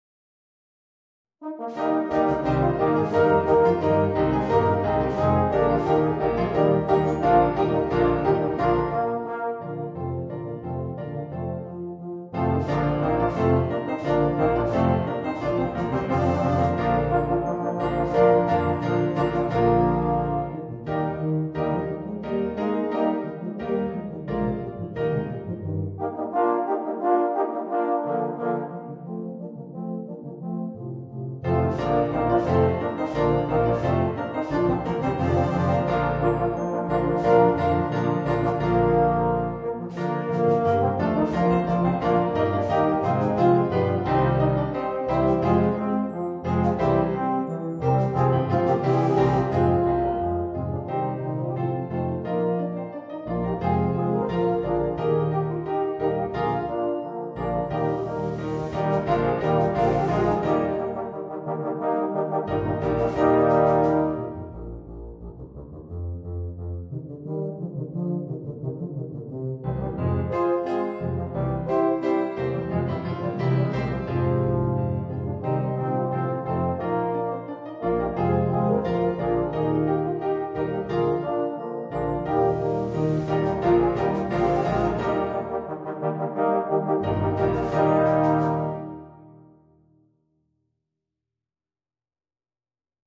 Voicing: 2 Baritones, 2 Euphoniums, 4 Tubas